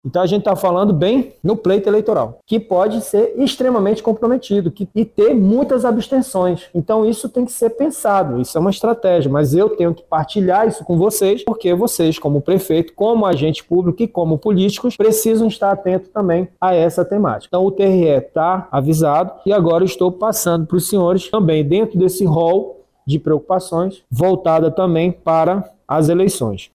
O Tribunal Regional Eleitoral do Amazonas (TRE-AM) já está ciente do fato, afirmou Coronel Máximo.